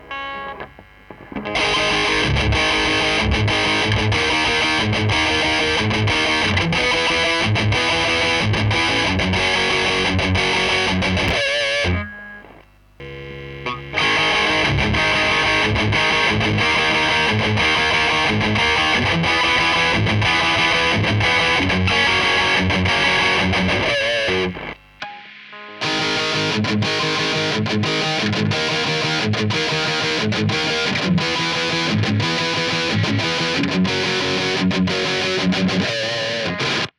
« : �������� 17, 2011, 19:27:26 » Какой тон больше нравится? 1, 2, 3 Опрос че-то нельзя сделать Перегрузы лампы, педальки и цифры в разном порядке.